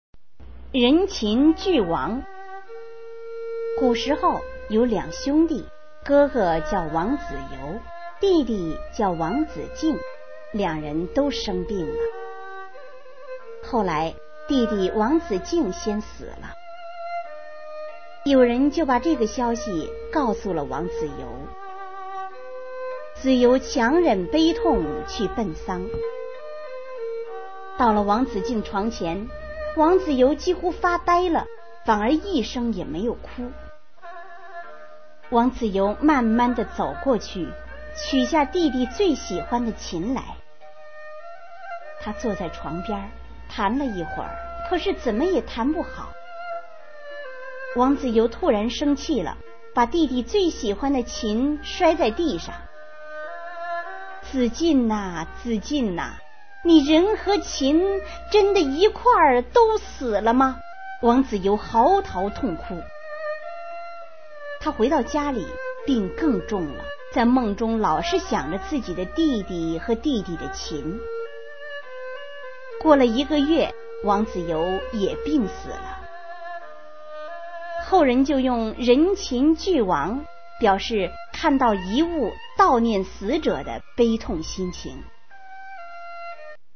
刘义庆《人琴俱亡》原文和译文（含朗读）